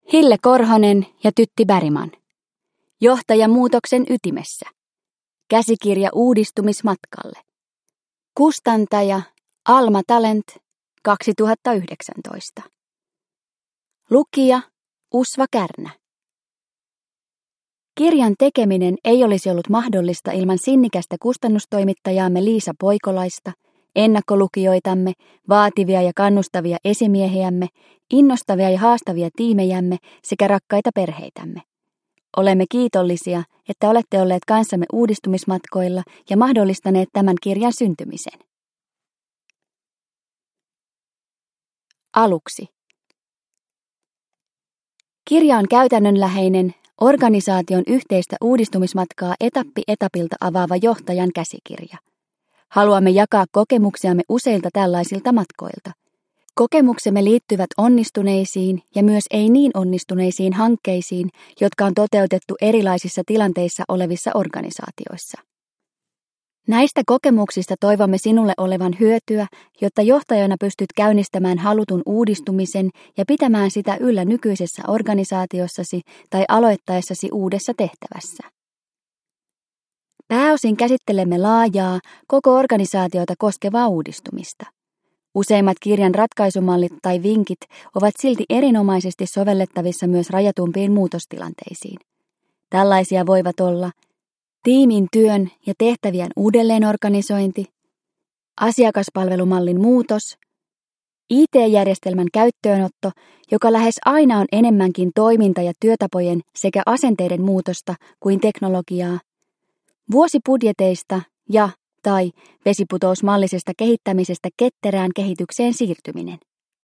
Johtaja muutoksen ytimessä – Ljudbok – Laddas ner